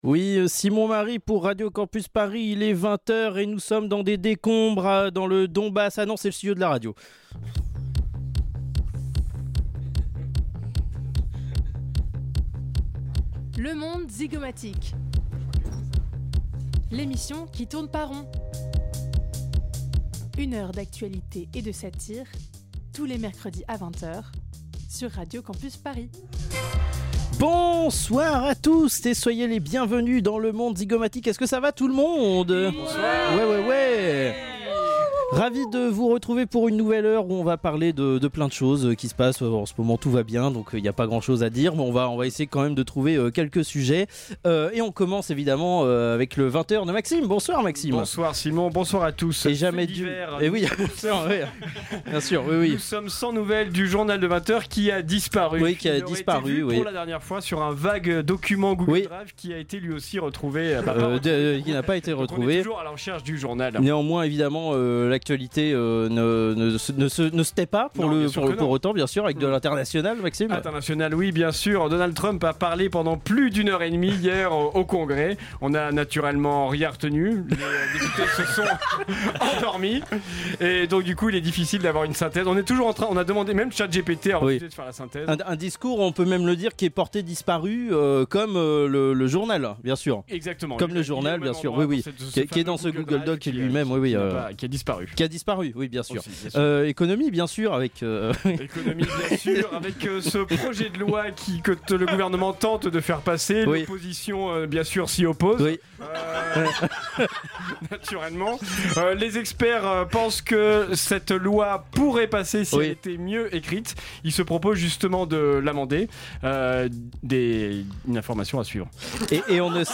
Le monde part en impro